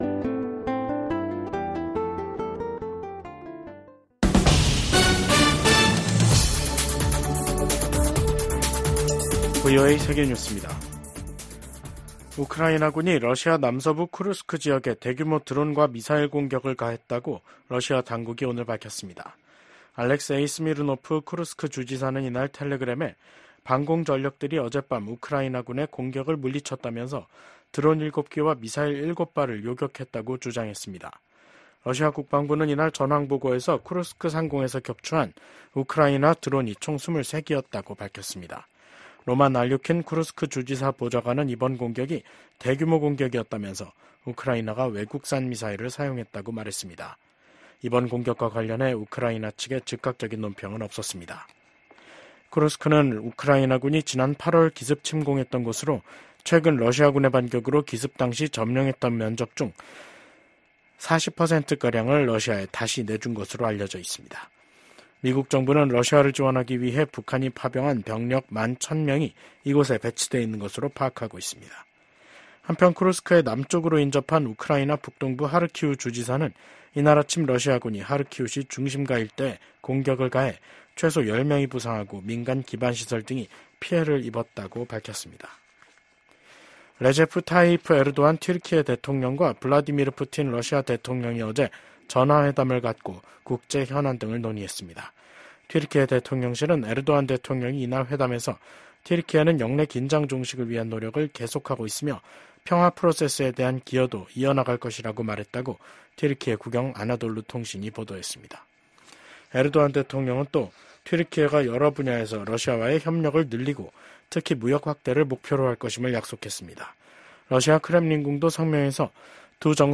VOA 한국어 간판 뉴스 프로그램 '뉴스 투데이', 2024년 11월 25일 3부 방송입니다. 러시아 고위 당국자가 한국이 우크라이나에 살상무기를 공급하면 강력 대응하겠다고 경고했습니다. 미국 백악관 고위 관리는 북한과 러시아의 군사적 관계 강화가 차기 행정부에도 쉽지 않은 도전이 될 것이라고 전망했습니다. 미국은 북한이 7차 핵실험 준비를 마치고 정치적 결단만 기다리고 있는 것으로 평가하고 있다고 국무부 당국자가 말했습니다.